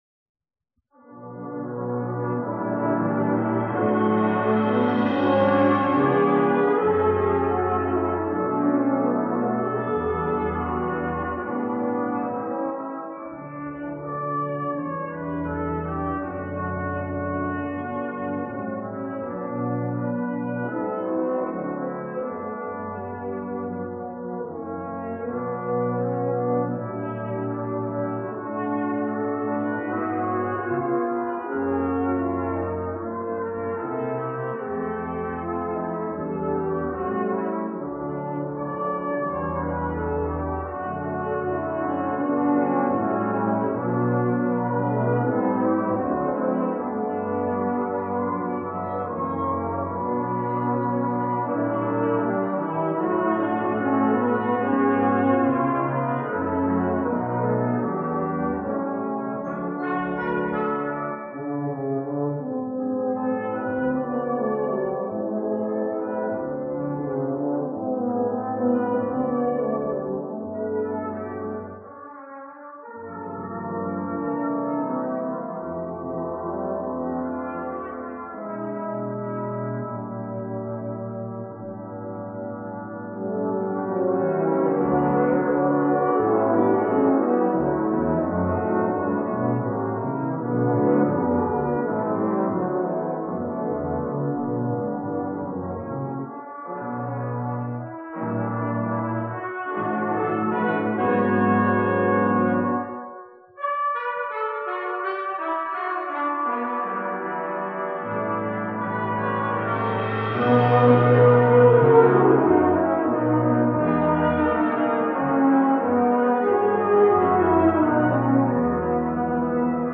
Southern Nazarene University presents the SNU Choral, Brass Choir and Choral Society in their annual Christmas concert : Christmas Sounds.